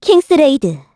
Hilda-Vox_Kingsraid_kr.wav